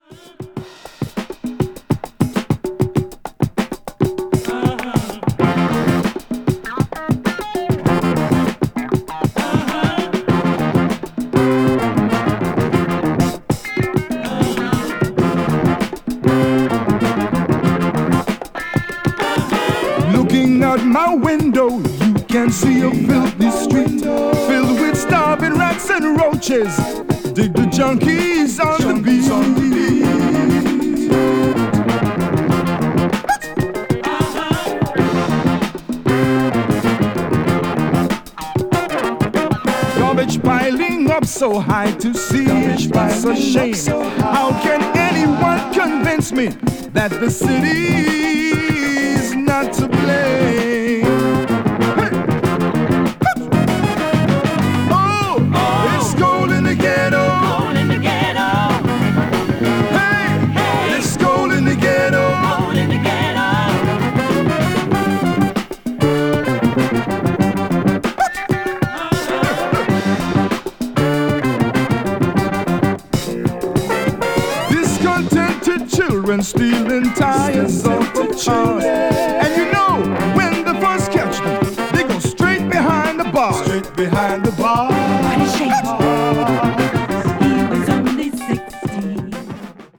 funk   jazz funk   rare groove   soul